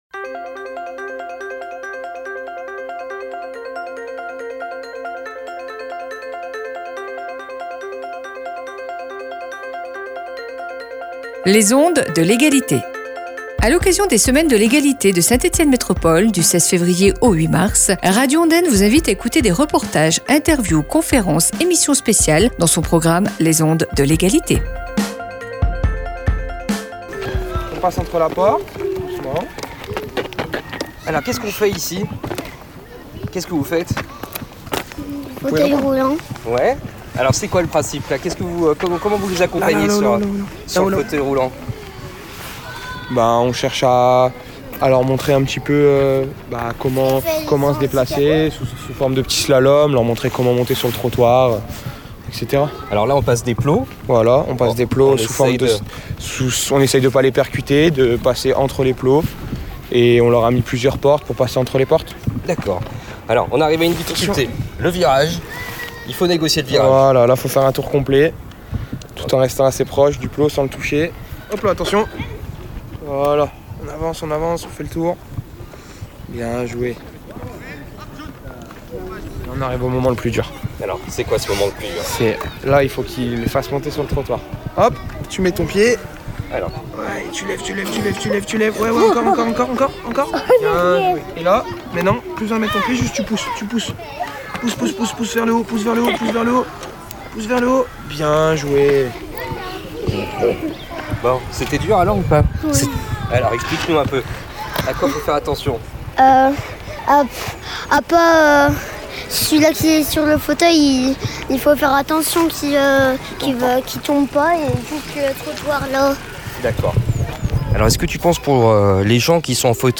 S’AMUSER, BOUGER, SE SENSIBILISER, FCO FIRMINY INSERSPORT, reportage Les Ondes de l’Égalité.
Depuis de nombres années, le FCO Firminy Insersport s’investit dans les Semaines de l’Égalité de Saint-Etienne Métropole pour proposer aux enfants et adolescents de la ville des ateliers de sensibilisation aux handicaps, à la lutte contre les discriminations, au vivre ensemble. Radio Ondaine, dans les Ondes de l’Égalité vous propose aujourd’hui à 11h30, un reportage sur ce projet réalisé le mercredi 04 Mars…